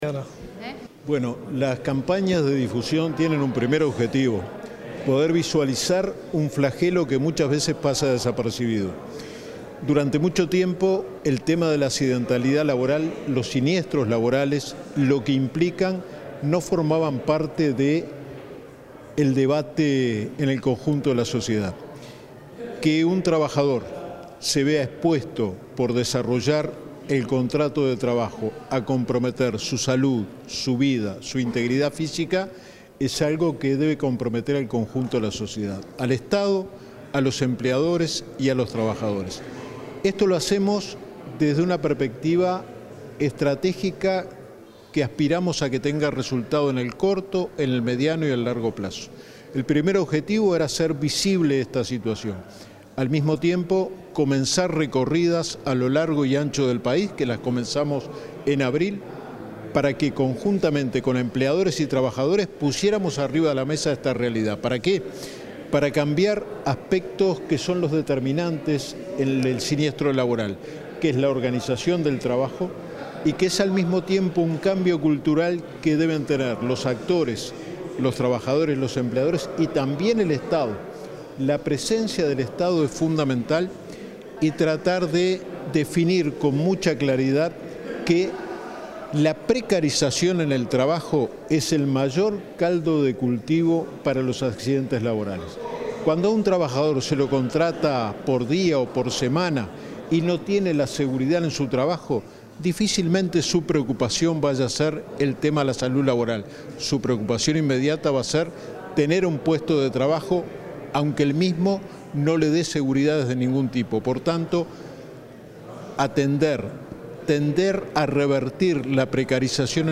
Declaraciones del inspector general del Trabajo y la Seguridad Social, Luis Puig